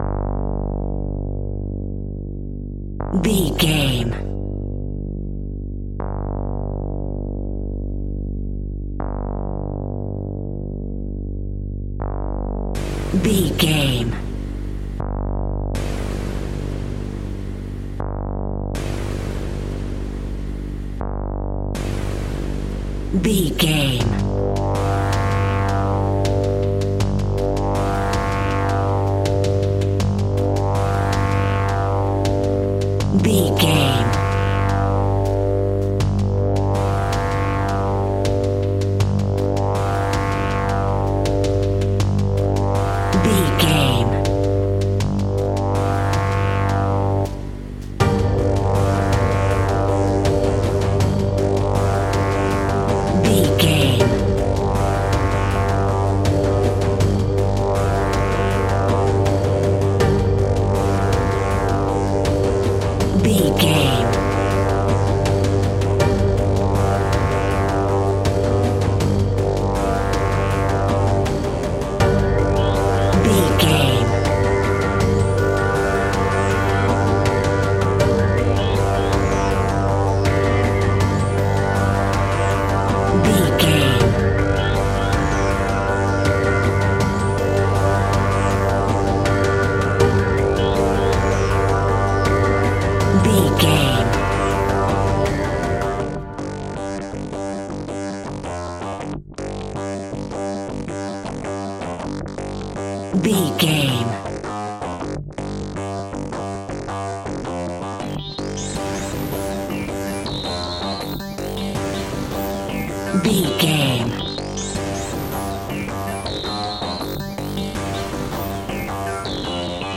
Aeolian/Minor
ominous
dark
disturbing
haunting
eerie
synthesiser
drums
tense
mysterious
ticking
electronic music